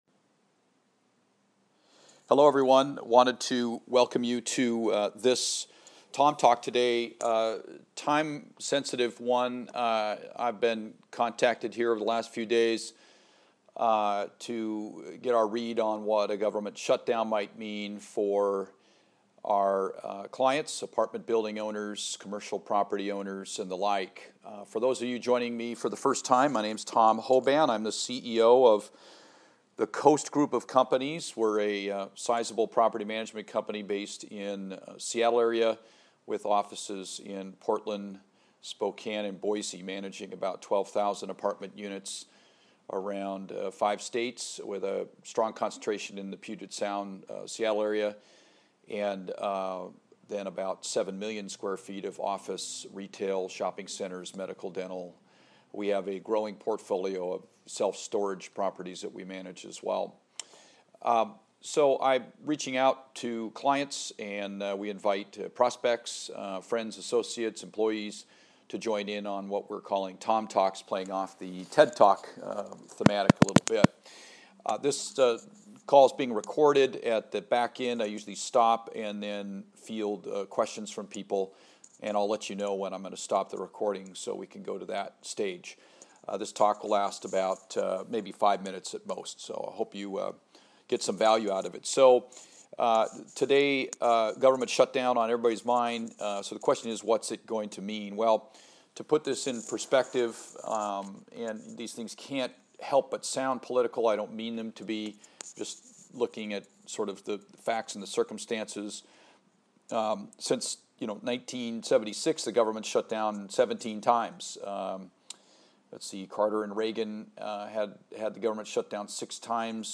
five minute talk